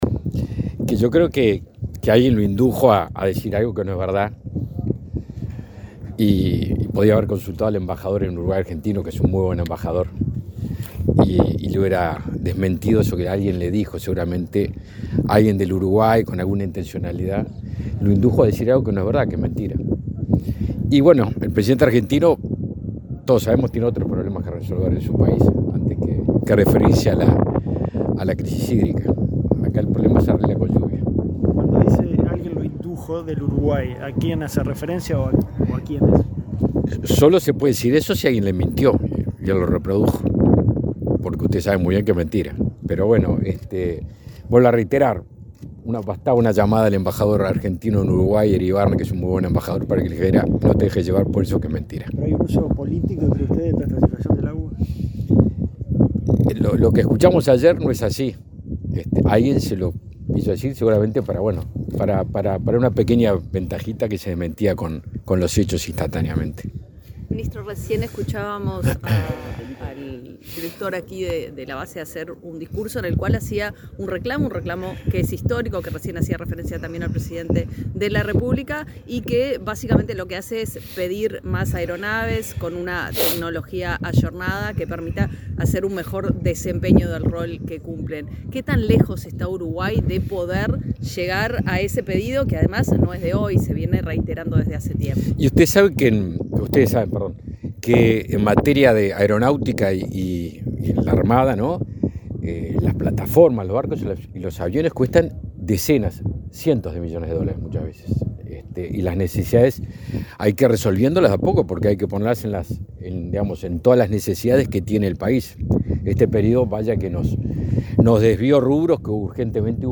Declaraciones del ministro de Defensa Nacional, Javier García
Luego dialogó con la prensa.